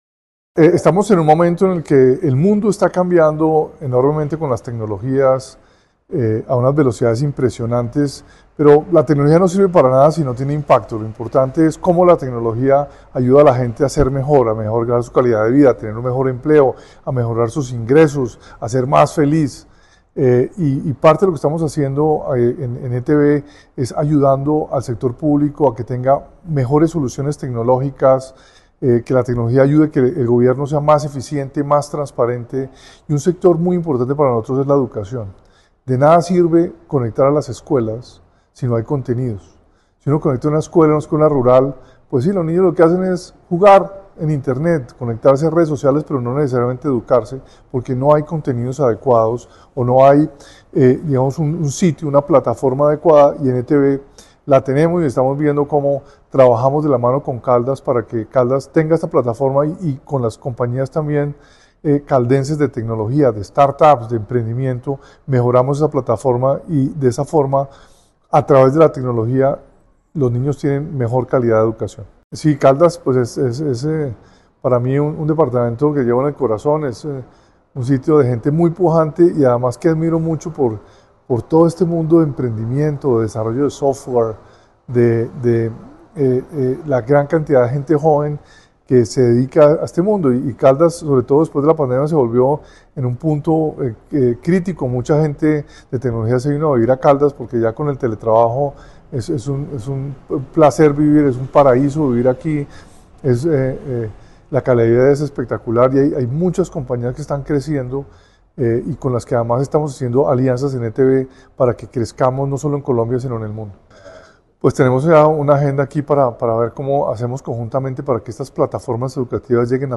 Diego Molano Vega, presidente de la Empresa de Telecomunicaciones de Bogotá (ETB)